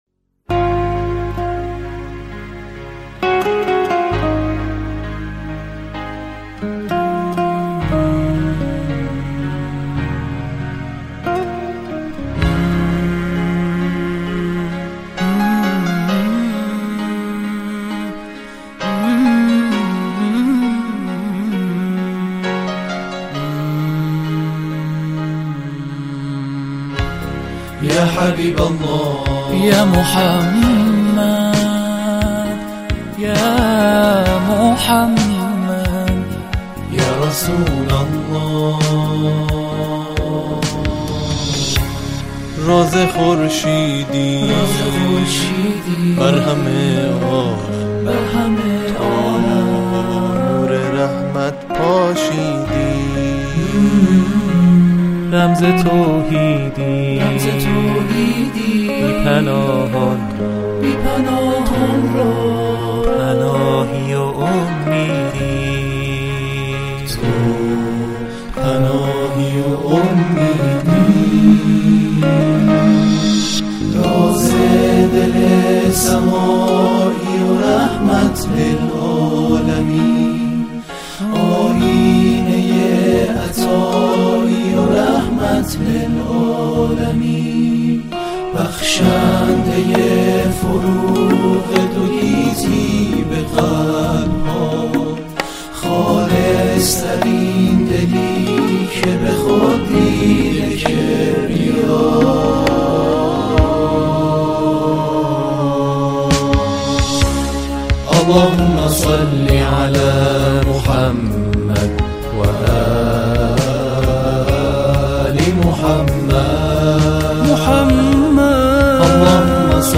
موسیقی الهی